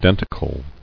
[den·ti·cle]